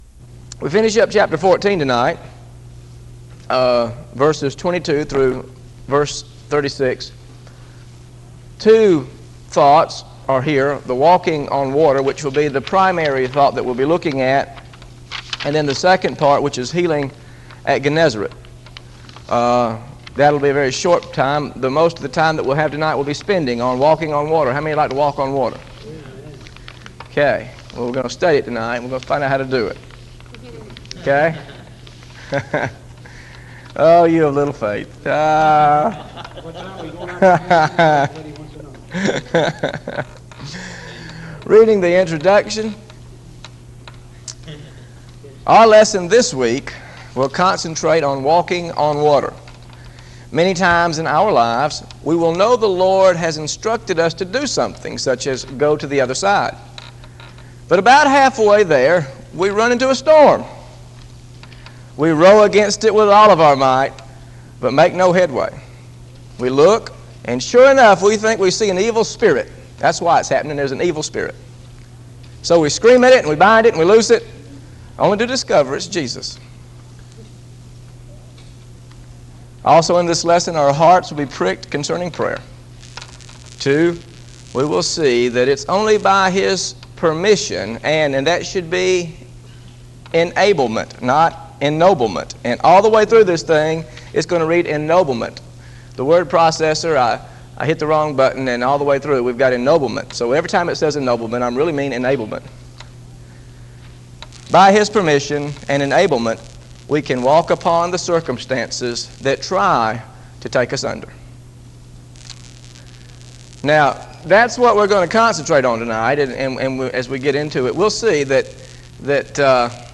GOSPEL OF MATTHEW BIBLE STUDY SERIES This study of Matthew: Matthew 14 Part 2 How to Trust Jesus Beyond the Boat is part of a verse-by-verse teaching series through the Gospel of Matthew.